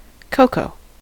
cocoa: Wikimedia Commons US English Pronunciations
En-us-cocoa.WAV